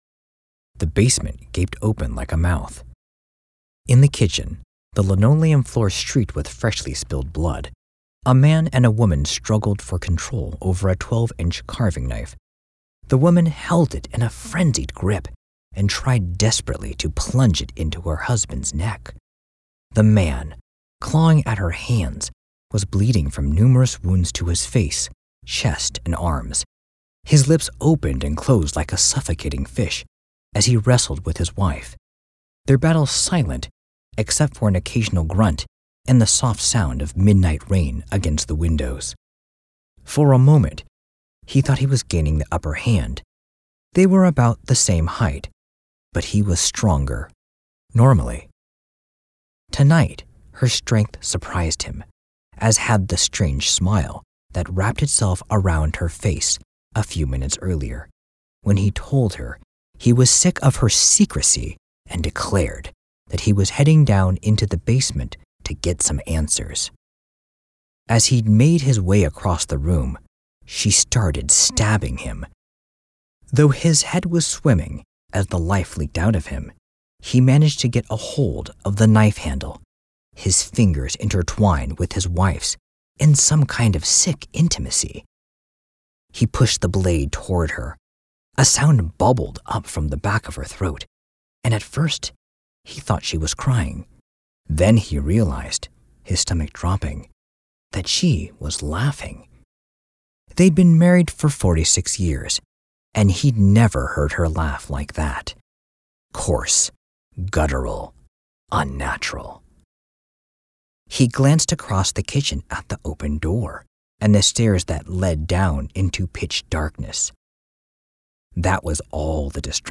Pro Sounding Luxury, High End, Calm Voice
Audiobook Demo